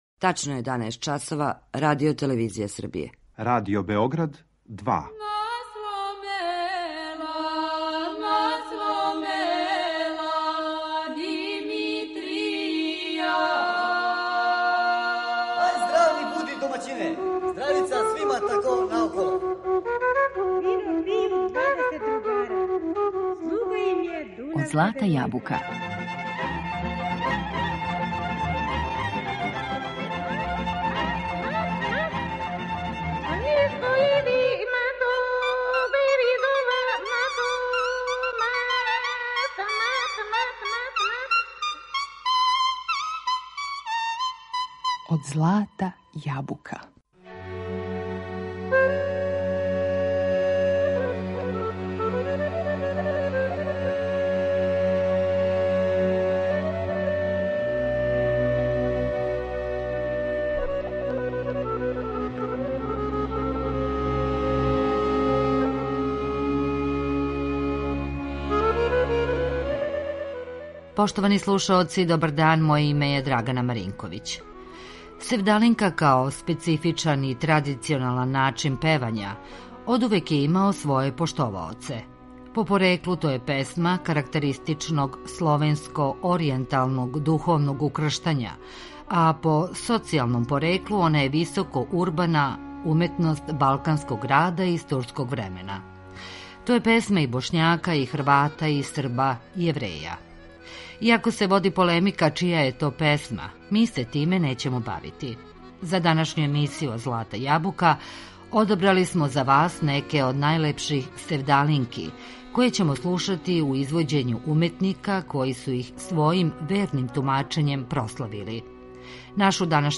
Севдалинка
Севдалинка ‒ као специфичан и традиционалан начин певања ‒ одувек је имала поштоваоце.
По пореклу, то је песма карактеристично словенско-оријенталног духовног укрштања, а по социјалном, она је високо урбана уметност балканског града из турског времена. У данашњој емисији Од злата јабука одабрали смо неке од најлепших севдалинки, а слушаћемо их у извођењу уметника који су их својим верним тумачењем прославили.